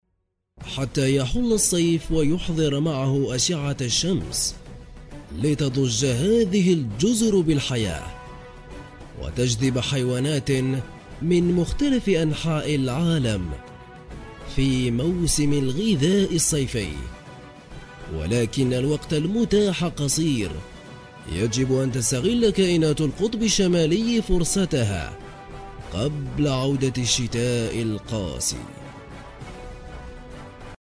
阿拉伯语翻译/阿语翻译团队成员主要由中国籍和阿拉伯语国家的中阿母语译员组成，可以提供证件类翻译（例如：驾照翻译、出生证翻译、房产证翻译，学位证翻译，毕业证翻译、成绩单翻译、无犯罪记录翻译、营业执照翻译、结婚证翻译、离婚证翻译、户口本翻译、奖状翻译、质量证书、许可证书等）、公证书翻译、病历翻译、公司章程翻译、技术文件翻译、工程文件翻译、合同翻译、审计报告翻译、视频听译/视频翻译、声音文件听译/语音文件听译等；阿拉伯语配音/阿语配音团队由阿拉伯语国家的阿拉伯语母语配音员组成，可以提供阿拉伯语专题配音、阿拉伯语广告配音、阿拉伯语教材配音、阿拉伯语电子读物配音、阿拉伯语产品资料配音、阿拉伯语宣传片配音、阿拉伯语彩铃配音等。
阿拉伯语样音试听下载
阿拉伯语配音员（男1） 阿拉伯语配音员（男2） 阿拉伯语配音员（男3） 阿拉伯语配音员（男4）